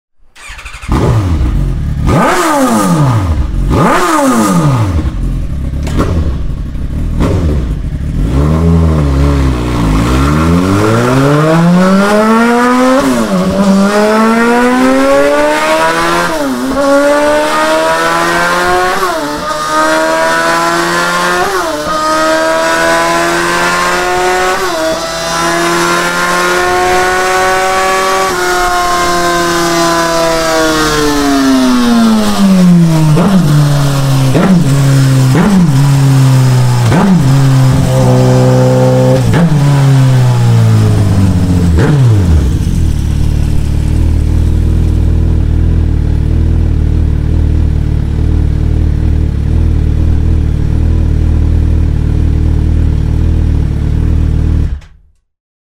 Звуки мотоциклов
Громкий рев спортивного мотоцикла на высоких оборотах